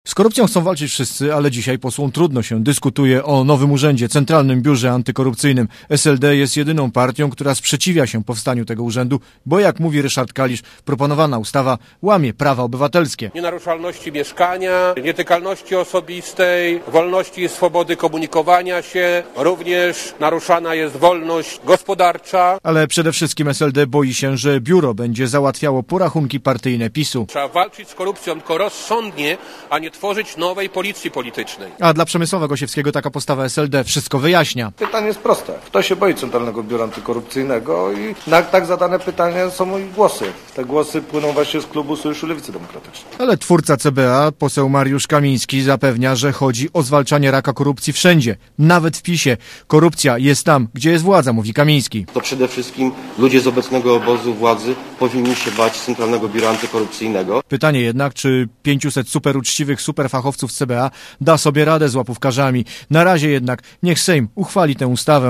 Źródło zdjęć: © RadioZet 16.02.2006 | aktual.: 17.02.2006 10:24 ZAPISZ UDOSTĘPNIJ SKOMENTUJ Relacja reportera Radia ZET